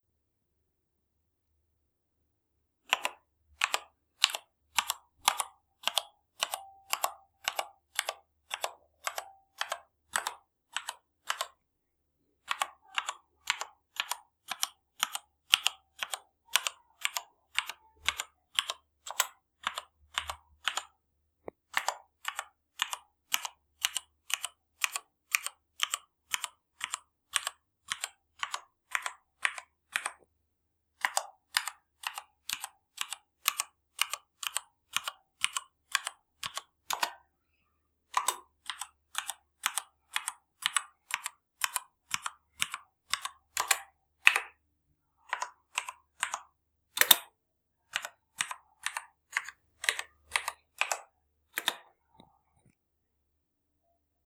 Keyboard
A mac style keyboard component with mechanical keys sound effects.